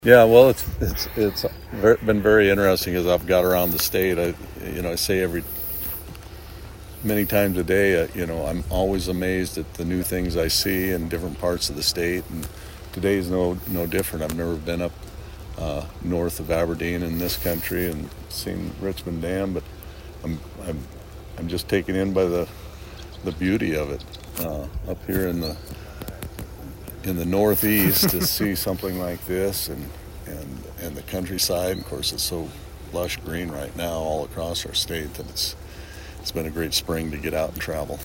Rhoden describe who he would be looking for legislators to represent those districts.